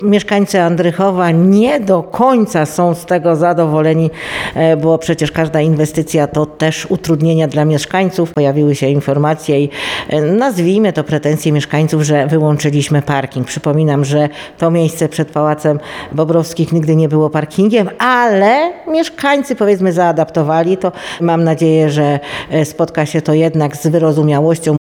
– Pałac Bobrowskich to jedna z tych inwestycji, która w końcu doczekała się realizacji, choć na razie mówimy tylko o jednym skrzydle i części, której właściwie nie widać, bo to fundamenty – mówi burmistrz Beata Smolec.